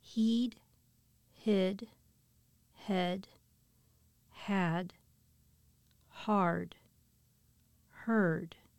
MINIMAL SETS
Listen to the different vowel sounds in these words.